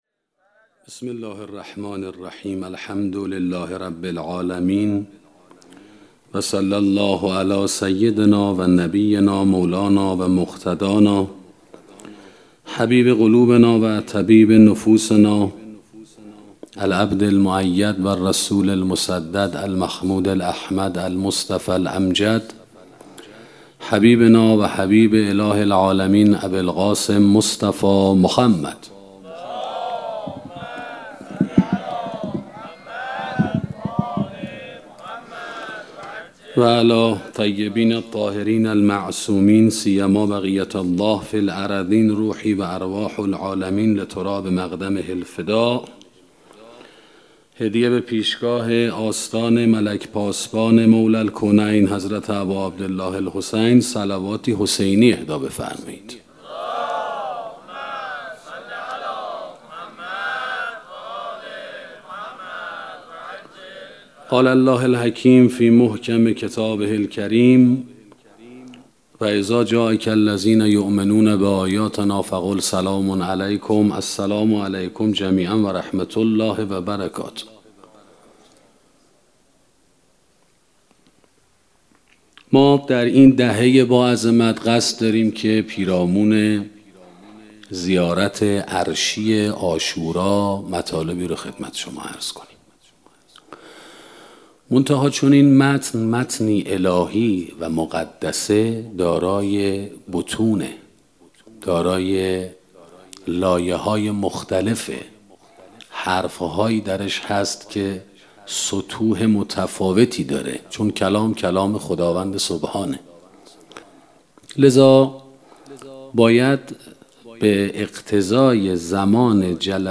سخنرانی شرح زیارت عاشورا 1